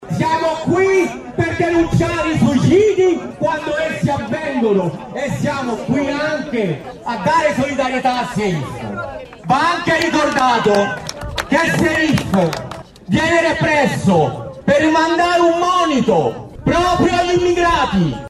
Ascoltiamo le voci dal presidio che si è tenuto nel fine settimana a Roma